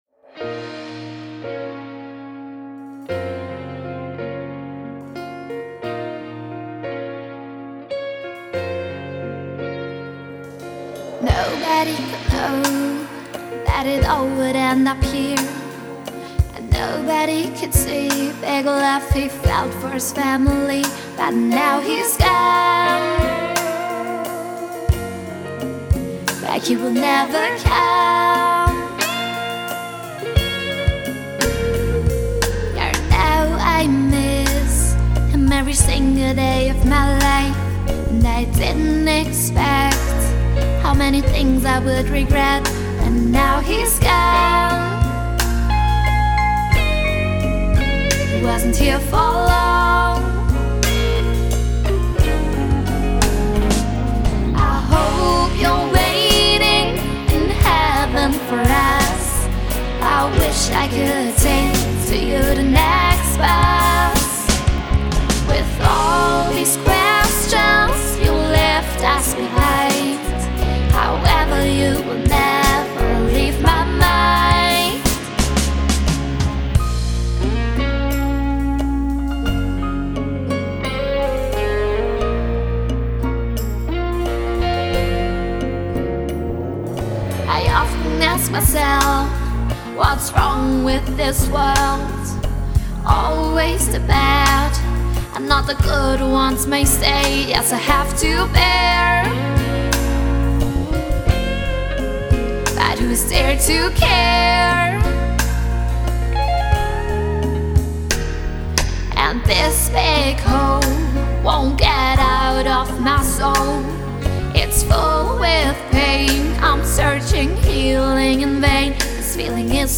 lead vocals
backing vocals
drums
piano; bass-, strings-, synth-, percussion progr.
guitars